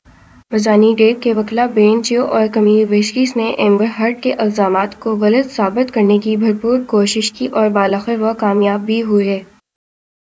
Spoofed_TTS/Speaker_12/262.wav · CSALT/deepfake_detection_dataset_urdu at main